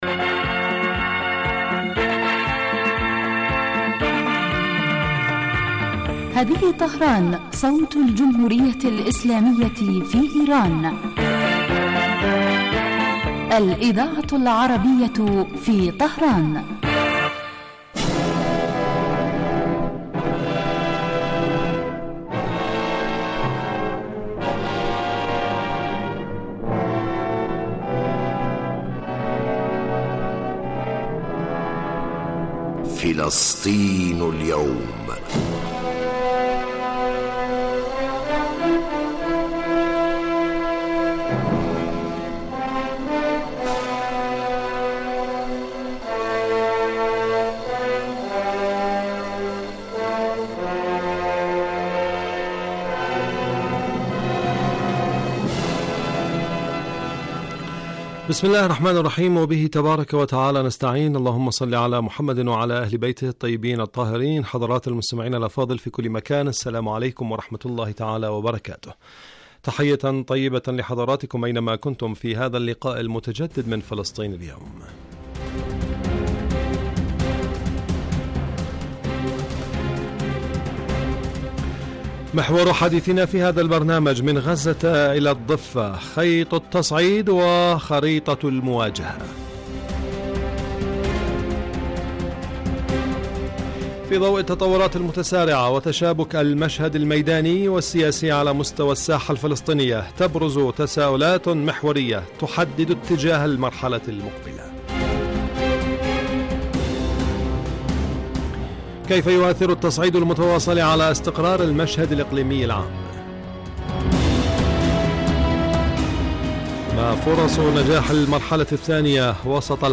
برنامج يتناول تطورات الساحة الفلسطينية على كافة الصعد من خلال تقارير المراسلين واستضافة الخبراء في الشأن الفلسطيني.